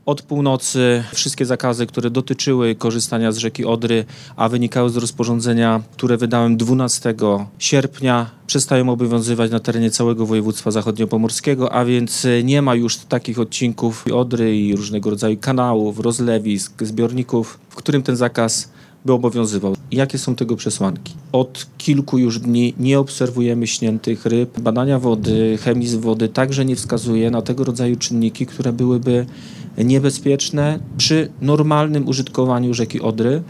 O północy przestał obowiązywać zakaz korzystania z Odry w całym regionie — podczas konferencji prasowej, poinformował wojewoda zachodniopomorski Zbigniew Bogucki.